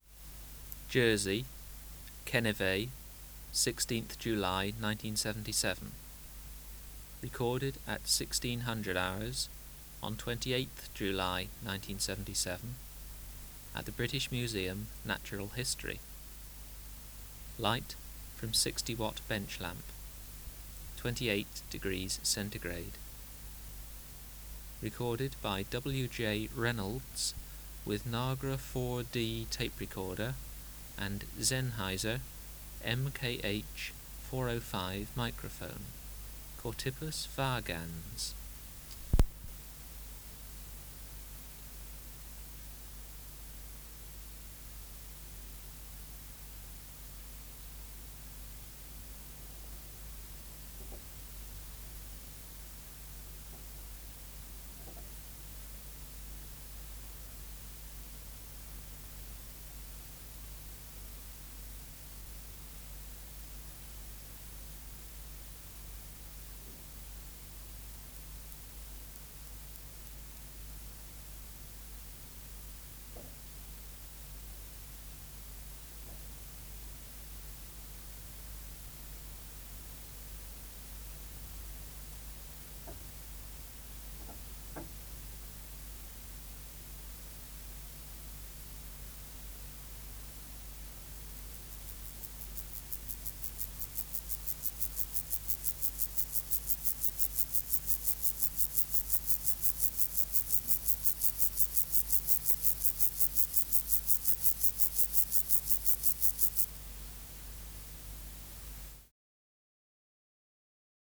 574:3 Chorthippus vagans (211a) | BioAcoustica
Recording Location: BMNH Acoustic Laboratory
Reference Signal: 1 kHz for 10 s
Substrate/Cage: Recording cage
Microphone & Power Supply: Sennheiser MKH 405 Distance from Subject (cm): 10